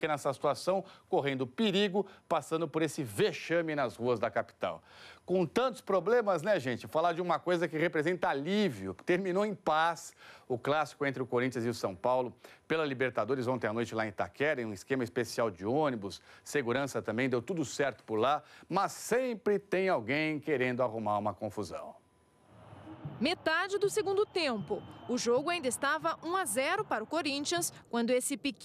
Note : When I played attached aac file in VLC the quality is good and does not have any glitch.